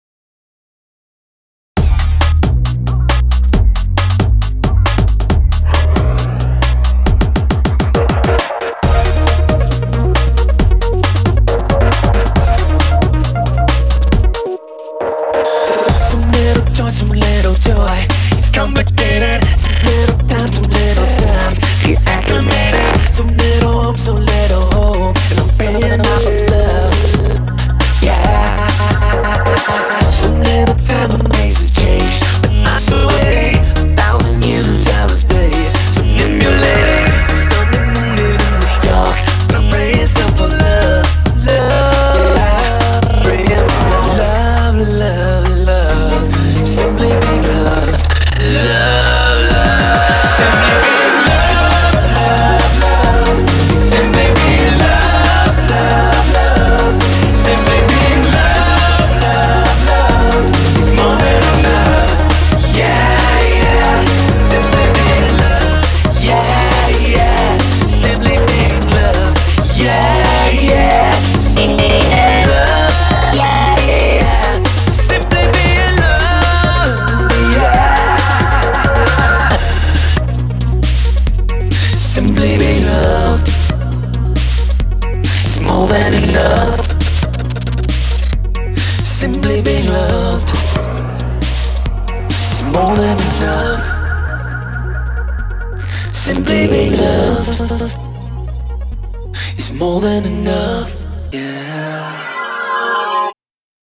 BPM135
Audio QualityPerfect (High Quality)
Comments[TRANCE POP]